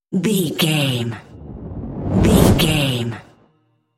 Whoosh fire ball
Sound Effects
whoosh